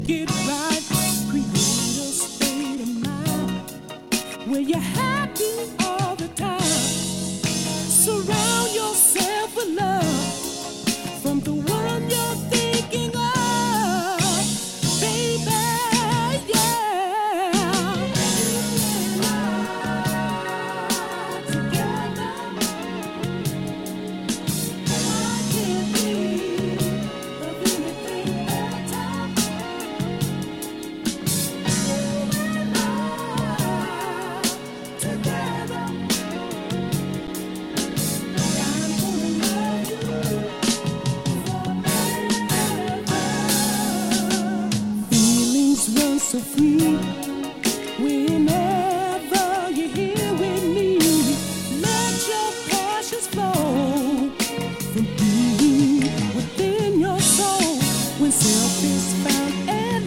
guitar
drums.